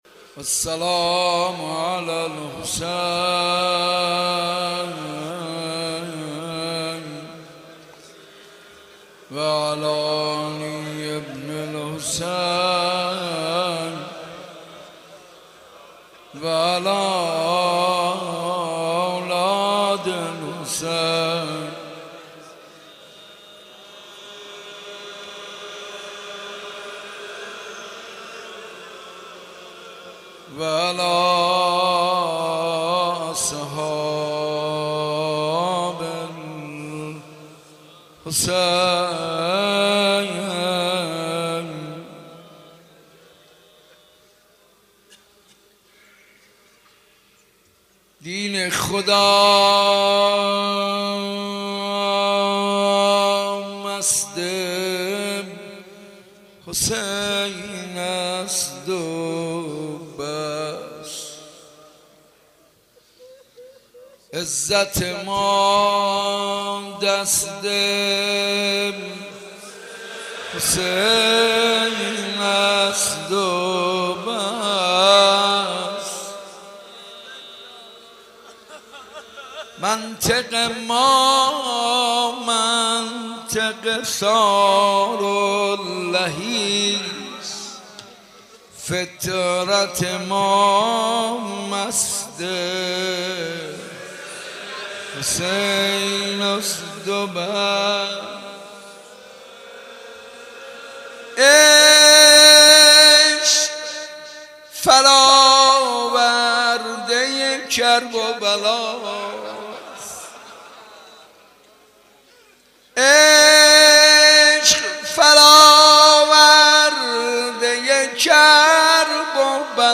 مناسبت : شب چهارم محرم
مجلس کامل